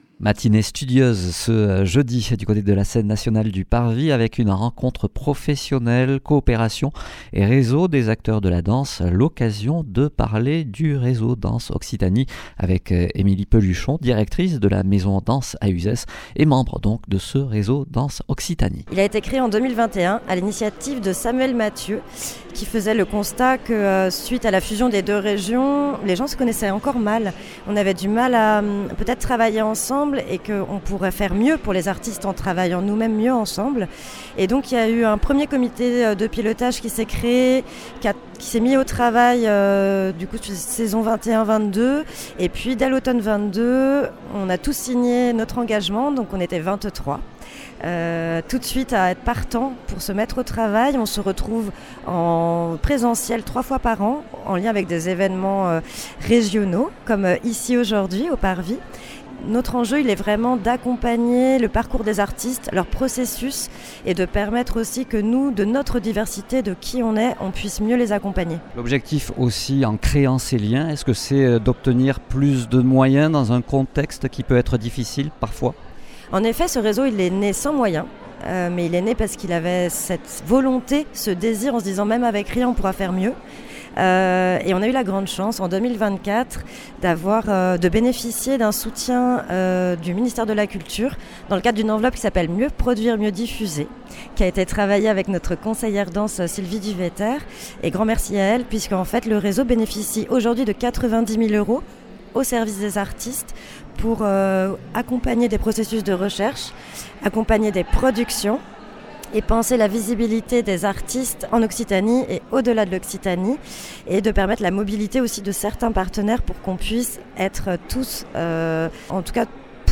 Lors du Fest’Nov à Tarbes, nous avons tendu le micro à plusieurs membres de structures du Réseau Danse Occitanie, réunis pour des séances de travail et de teambuilding.